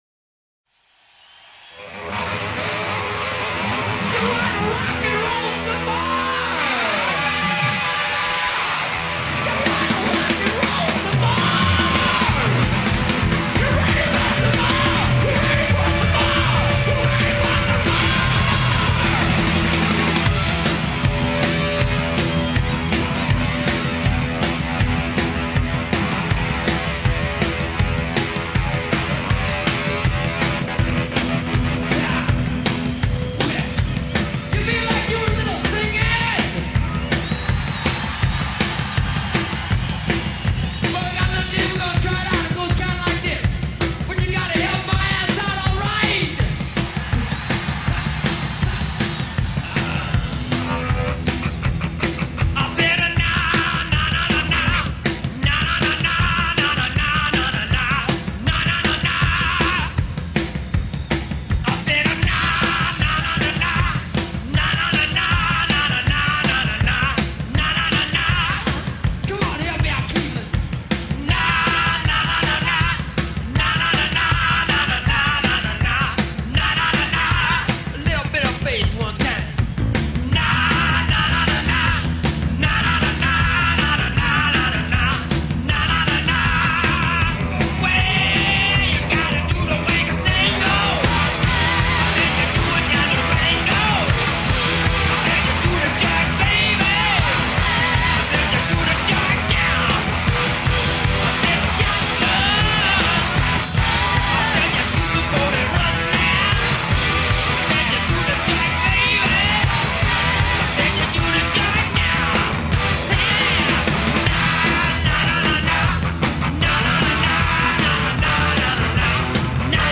Гаражный, отвязный дворовой трэш с гитарными запилами
рок
хард-рок